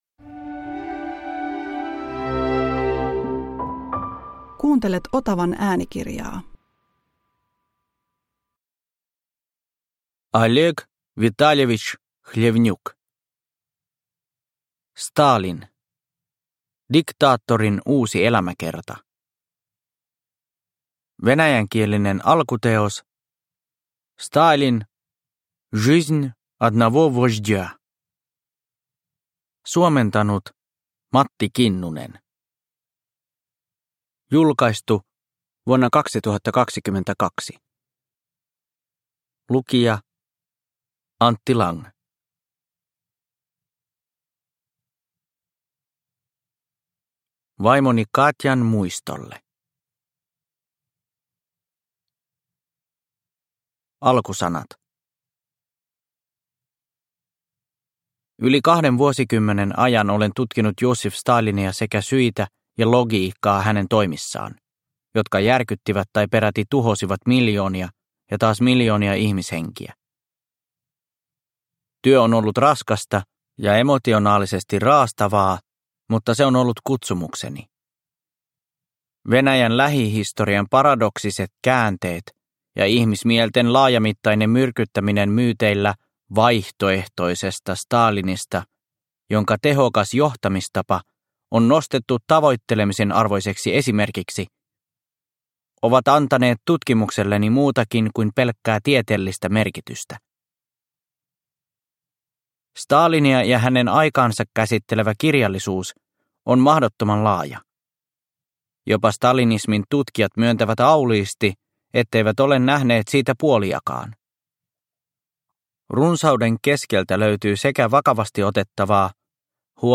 Stalin – Ljudbok – Laddas ner